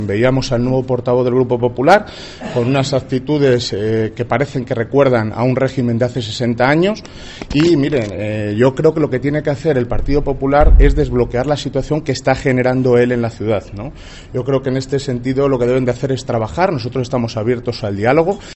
Alcalde de Ávila, sobre la actitud de portavoz del PP en el Ayuntamiento, Jorge Pato